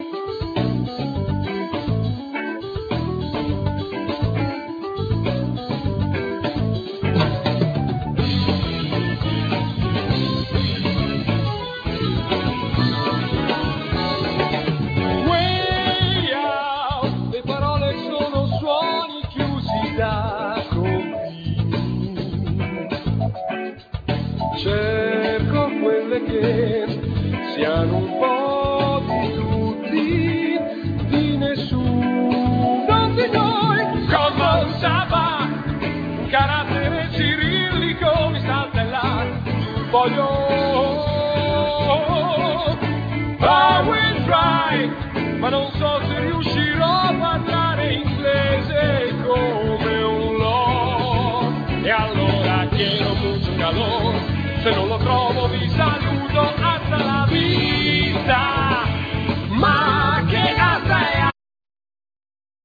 Keyboards,Vocal
Drums,Percussions,Vocal
Bass
Guitar